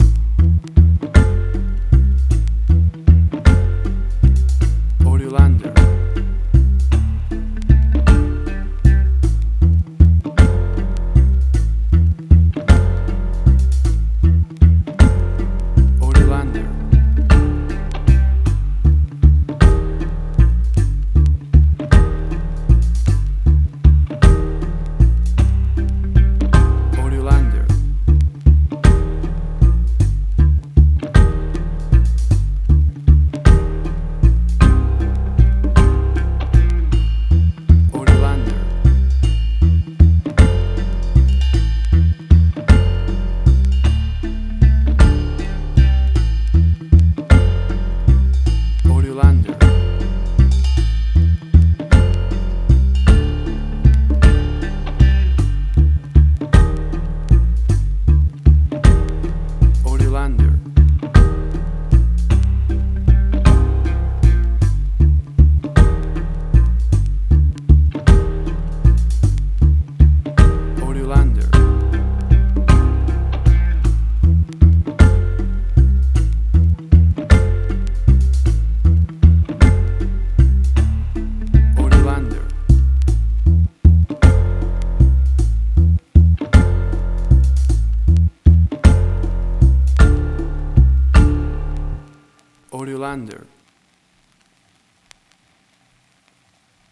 Reggae caribbean Dub Roots
Tempo (BPM): 52